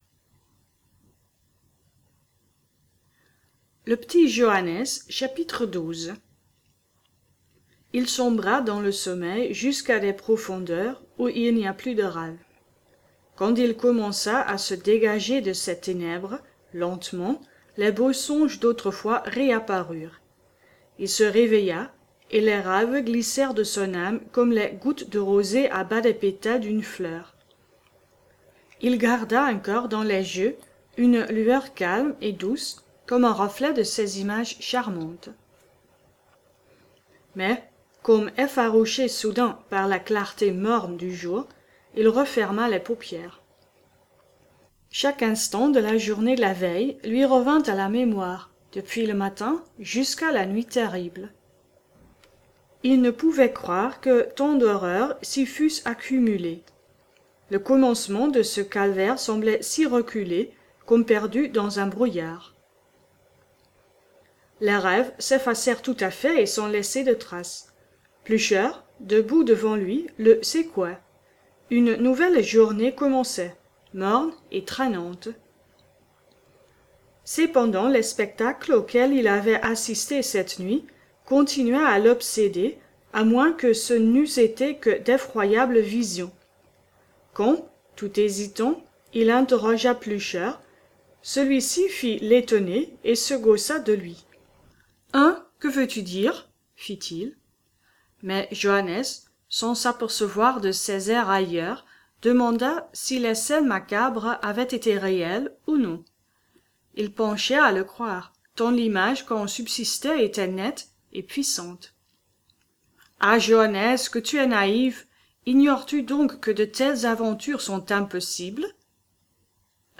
Genre : Contes
> Télécharger ce livre audio par archive(s) Zip [ Aide ] : Le Petit Johannes.zip > Télécharger ce livre audio par chapitres [ Aide ] : Chapitre 01.mp3 (Clic-droit, « Enregistrer sous…